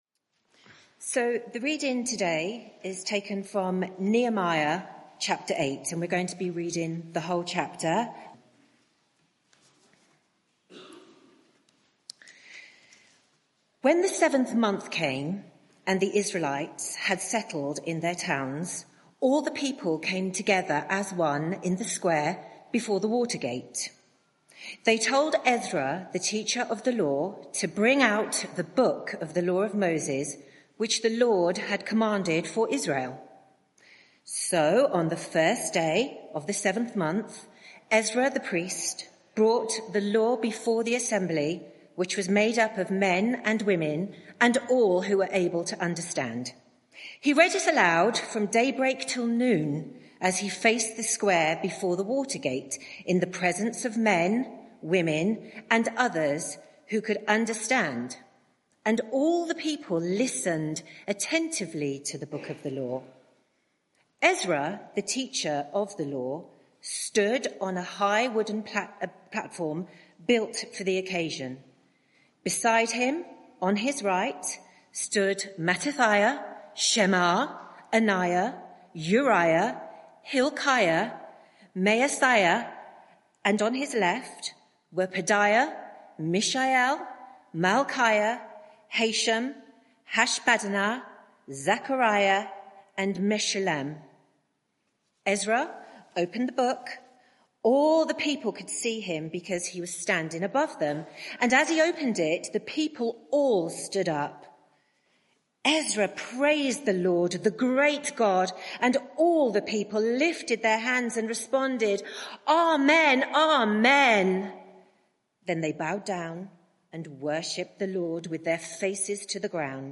Media for 11am Service on Sun 26th May 2024 11:00 Speaker
Sermon (audio)